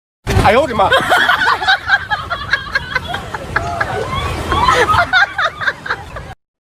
Funny Sound Effects Soundboard: Play Instant Sound Effect Button